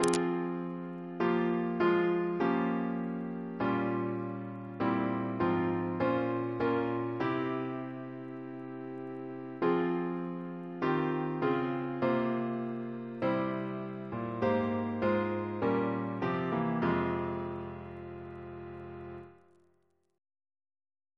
Double chant in F minor Composer: Chris Biemesderfer (b.1958)